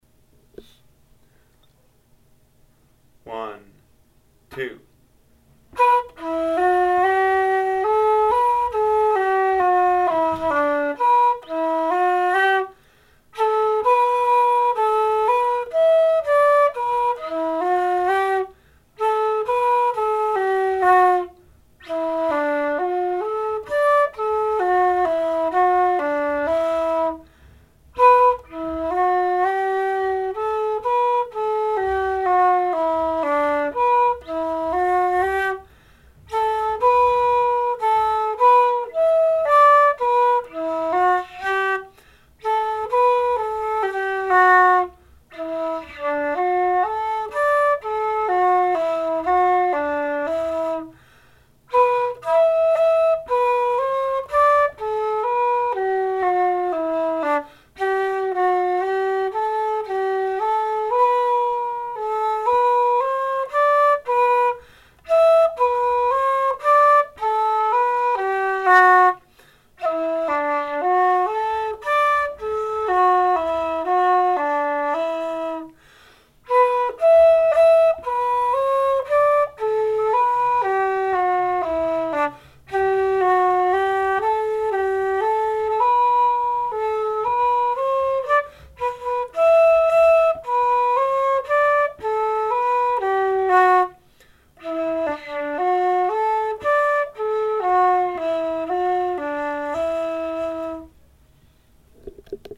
JIGS - The Connaughtman's Rambles & The Thatcher
Thatcher-slow.mp3